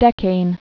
(dĕkān)